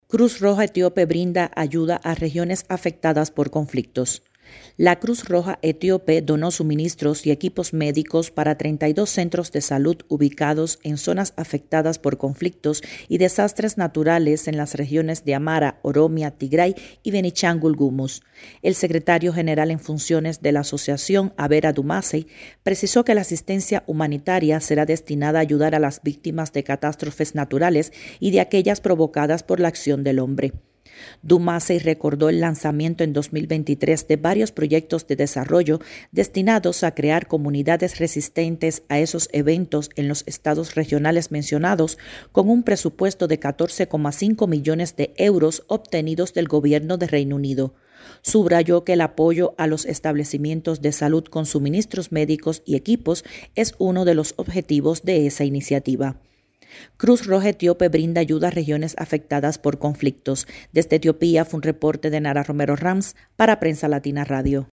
desde Addis Abeba